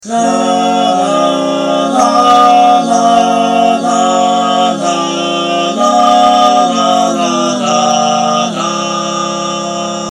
😀 So, here I am singing in three-part harmony with myself:
la.mp3